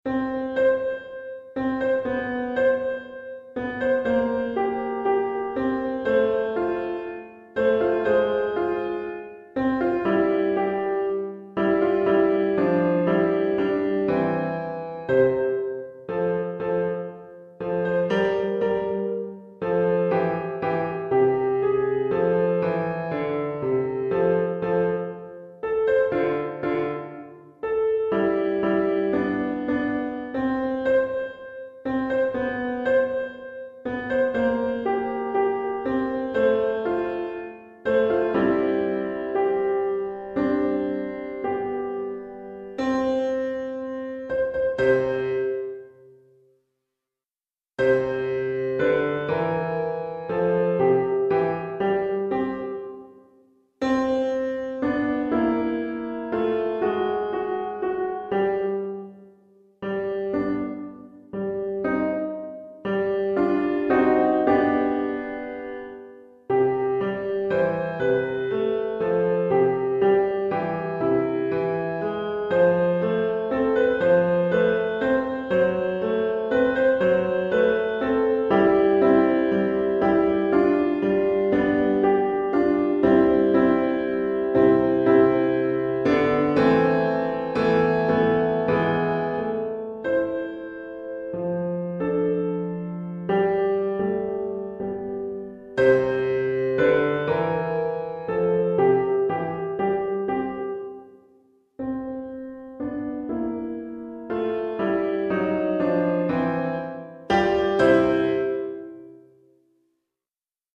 Pour piano solo
Piano solo